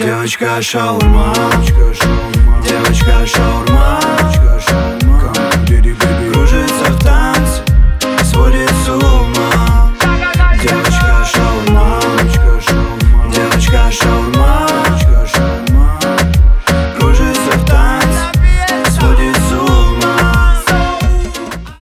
• Качество: 320, Stereo
поп
спокойные